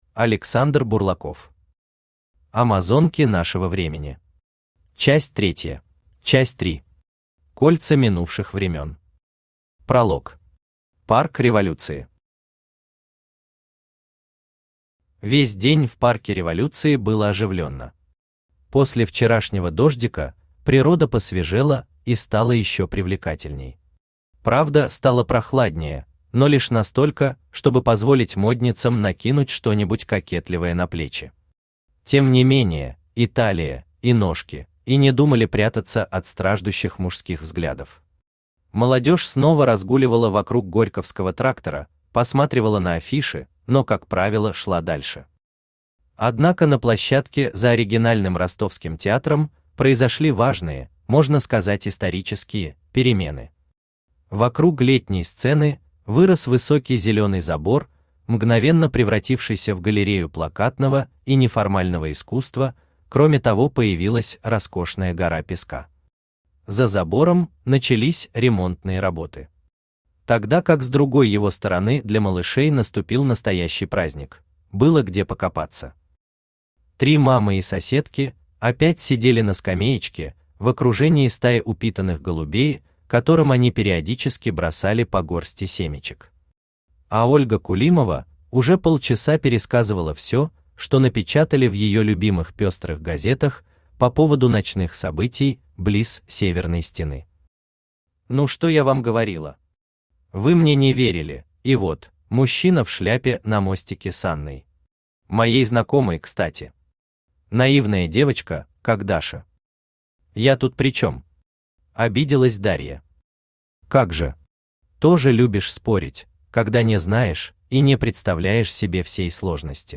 Аудиокнига Амазонки нашего времени. Часть Третья | Библиотека аудиокниг
Aудиокнига Амазонки нашего времени. Часть Третья Автор Александр Бурлаков Читает аудиокнигу Авточтец ЛитРес.